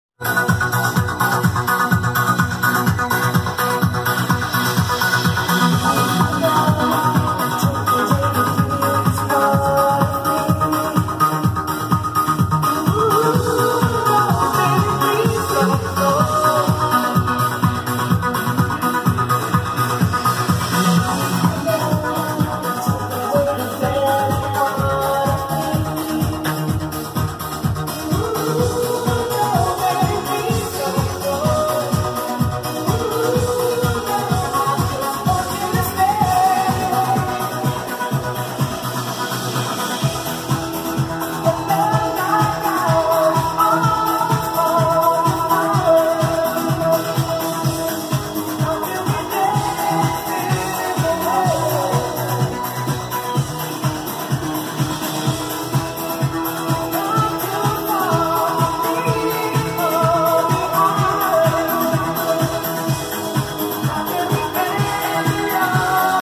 Testing my setup for an upcoming gig. This AV-25BT amp and classic Yamaha NS-6490 speakers rock! And that's not even using my powered sub ... yet!